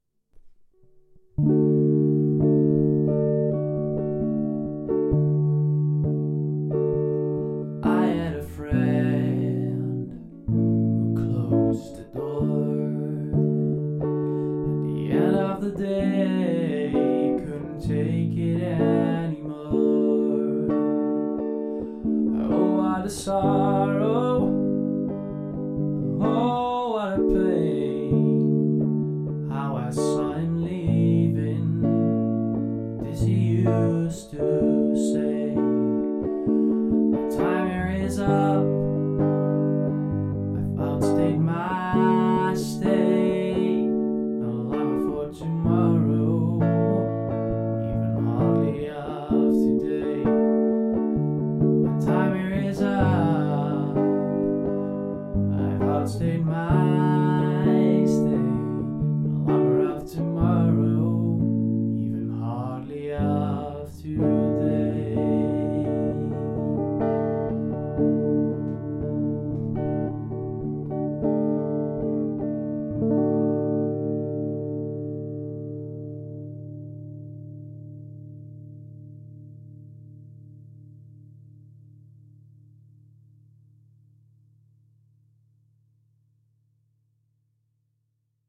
Boem, bijna direct erachteraan kwam een melancholiek ‘tussendoortje’, zijn versie van U Mag Mij Wegstemmen, waar hij eens zoveel tekst erbij schreef als dat het oorspronkelijke gedicht bevatte.